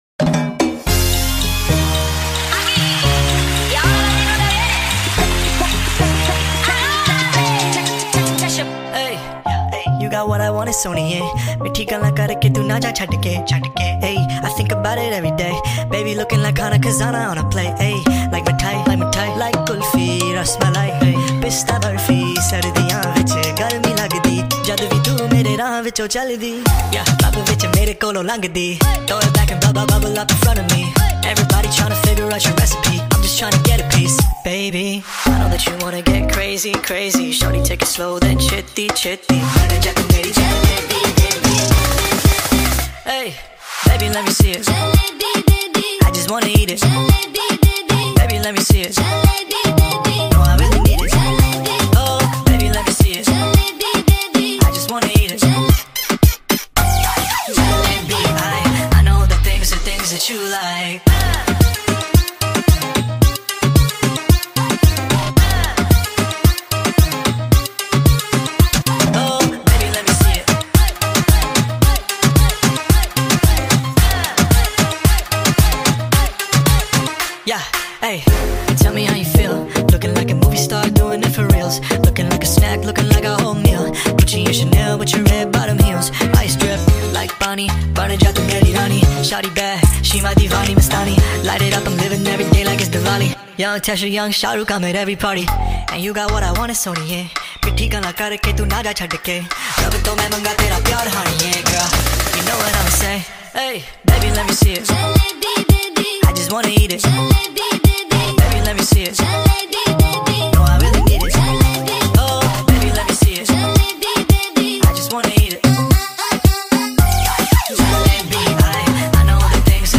نسخه سریع شده و Sped Up